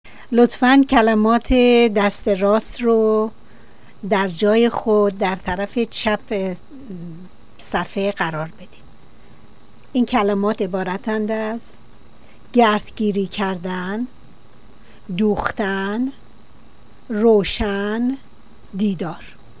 Listen to directions and the words pronounced!